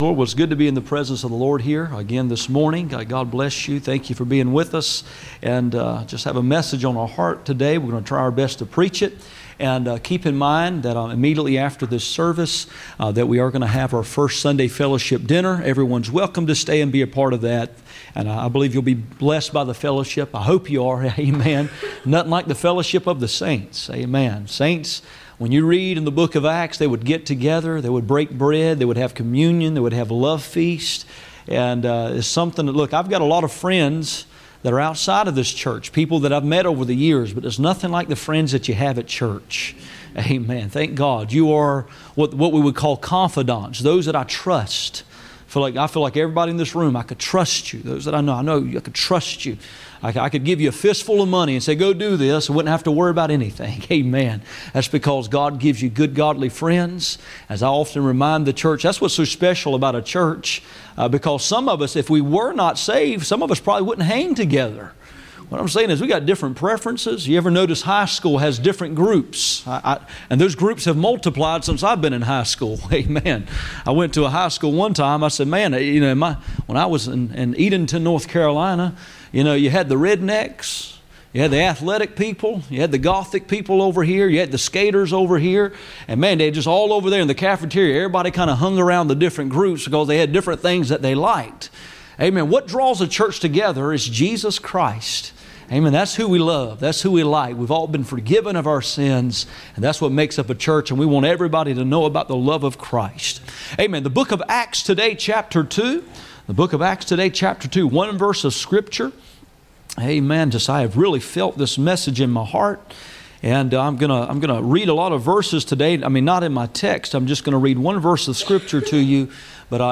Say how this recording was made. Acts 2:4 Service Type: Sunday Morning %todo_render% « What did God do with my sins